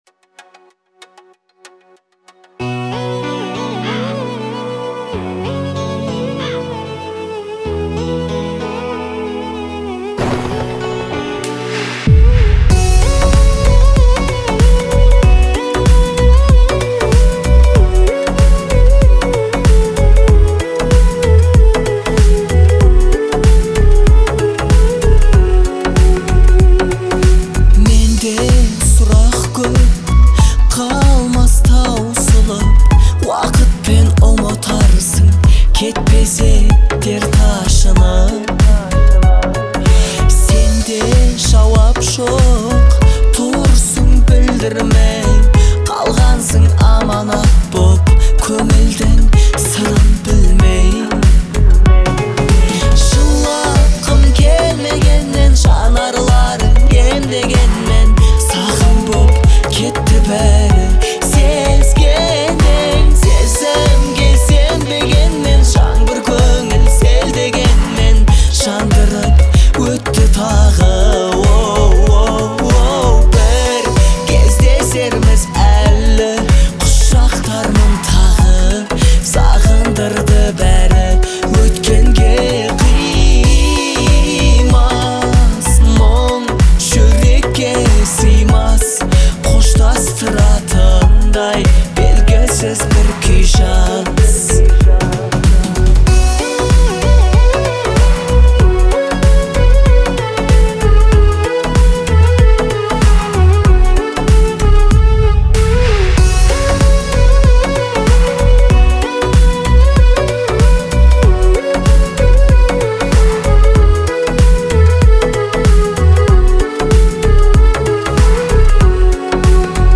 это трогательная песня в жанре казахского поп-фолка